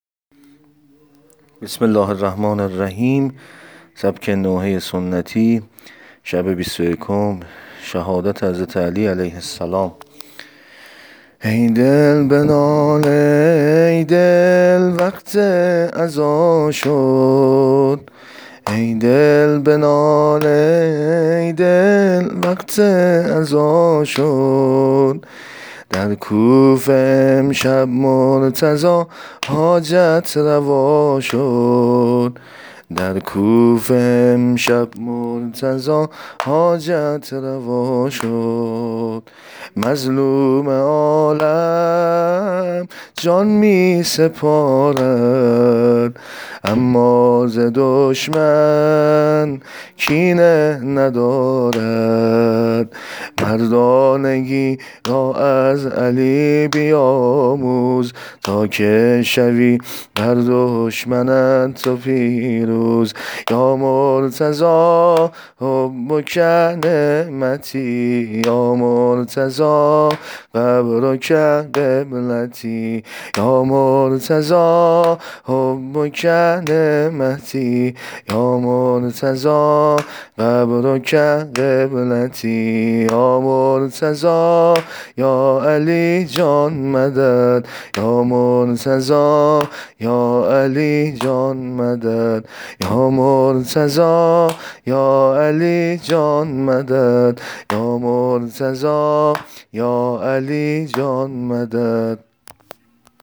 نوحه سنتی،مسجدی شب بیست و یکم شهادت حضرت علی علیه السلام
عنوان : سبک نوحه شهادت حضرت علی (ع